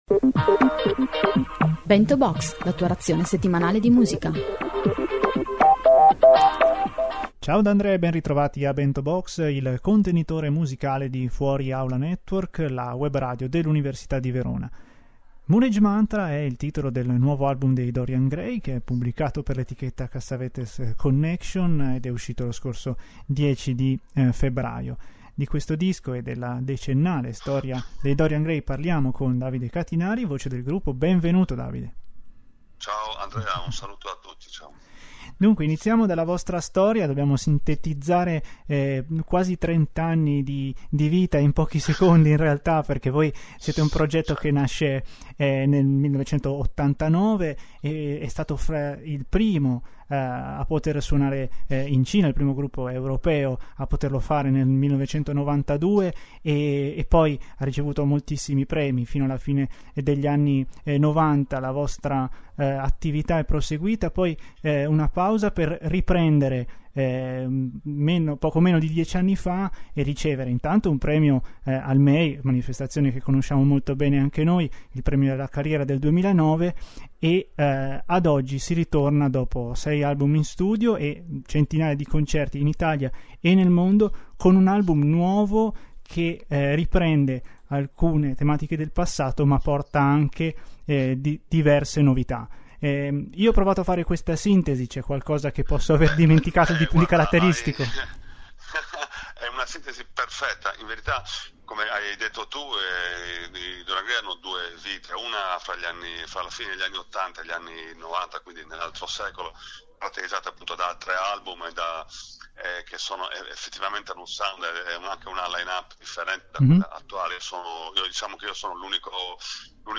Ospiti di questa settimana i Dorian Gray, storica formazione del indie rock italiano.